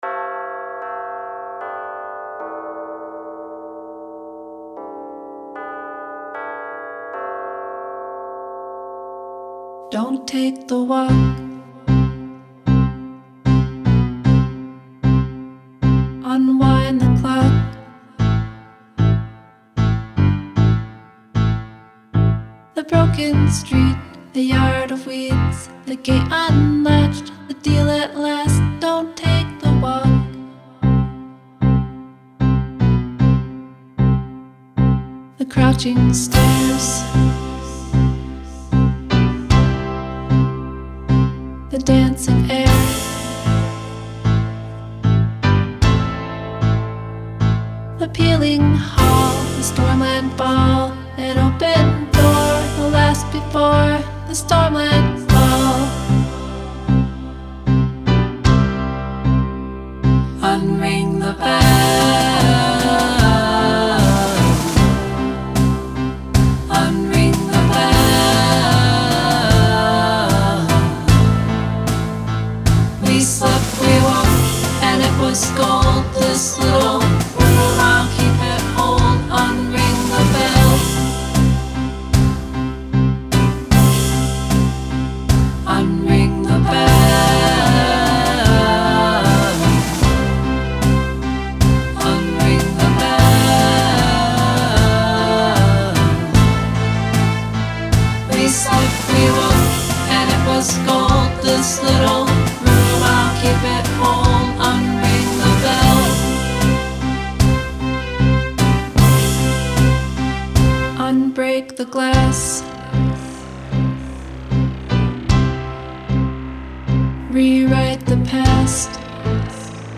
Finding a way to keep the 'dark' feel and also get the most from the song was a bit of a challenge.
I found a different kick sample that seems to work better.
In this case I found a way to use a few different ones to get the depth without throwing everything into the abyss.
I opted for pulling back on the instruments at that point to give the second verse a more intimate feel.
I ended up recording a bass guitar and guitar tracks in parts to build up to the choruses which seemed to help the mix come together.